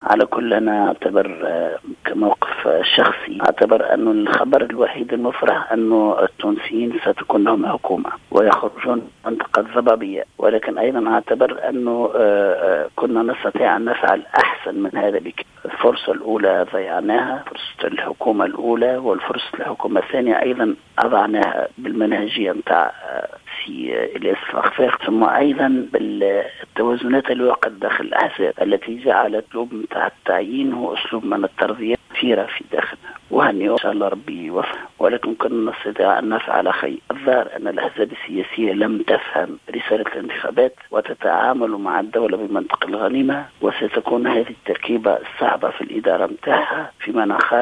اعتبر القيادي في النهضة عبد الحميد الجلاصي في تصريح للجوهرة "اف ام" أن الخبر الوحيد المفرح اليوم هو أنه سيكون للتونسيين حكومة تمكن من الخروج من منطق الضبابية.